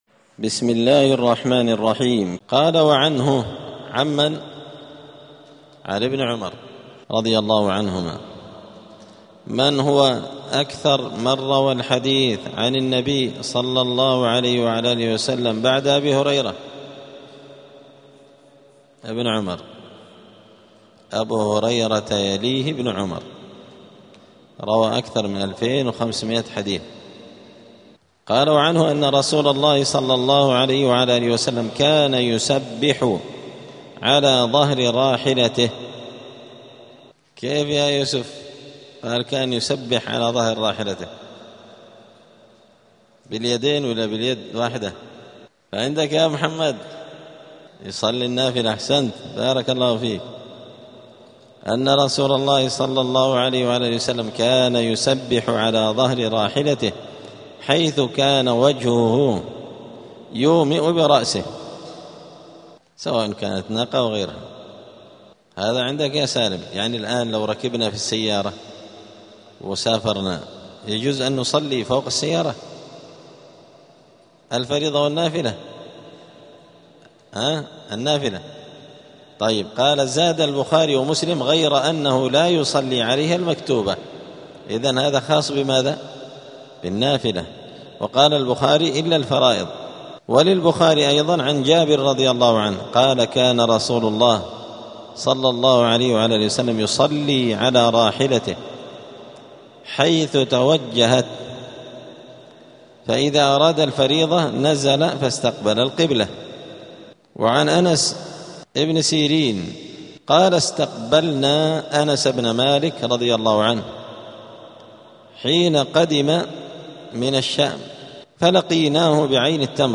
دار الحديث السلفية بمسجد الفرقان قشن المهرة اليمن
*الدرس الخامس والسبعون بعد المائة [175] باب استقبال القبلة {حكم صلاة النافلة على الراحلة حيث توجهت به}*